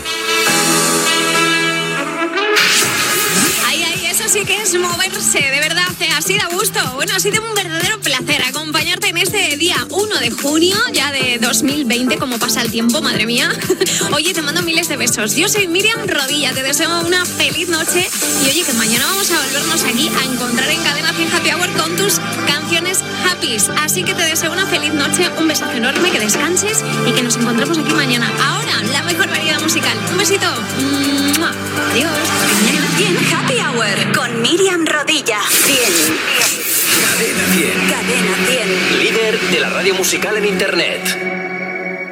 Data i comiat del programa amb indicatiu de la ràdio.